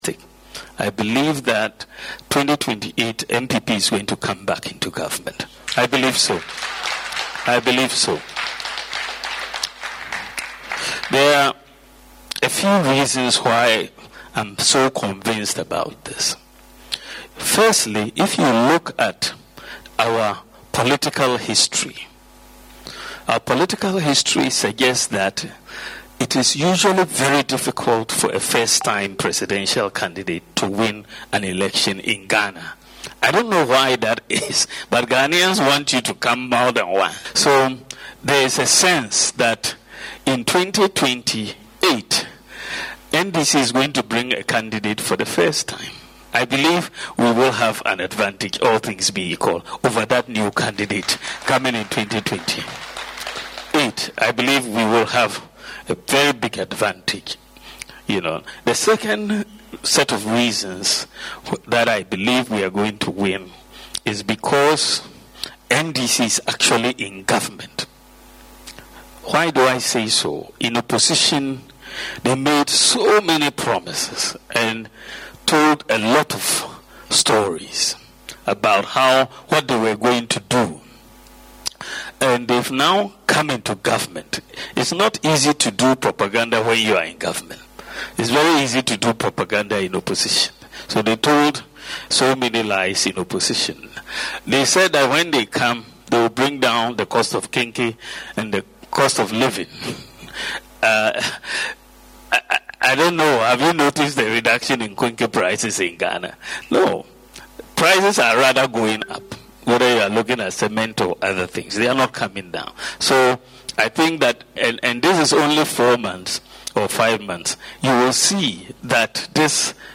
Addressing members of the NPP in London during a meeting with the party’s diaspora branch, Dr Bawumia stated that historical trends in Ghana’s political landscape favour opposition parties.